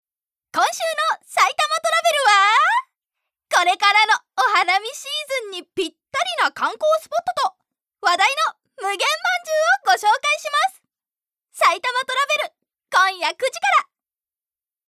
ボイスサンプル
ナレーション②(番組)